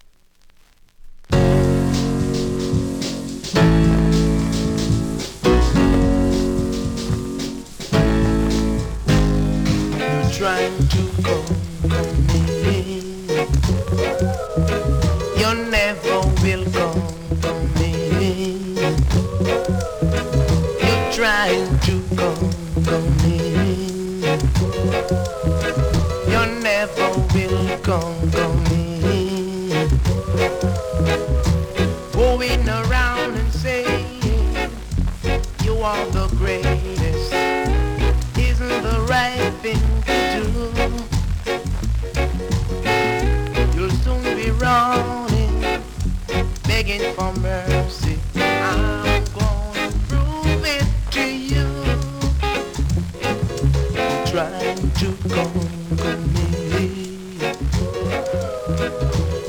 プレス起因のノイズ感じますので試聴で確認下さい。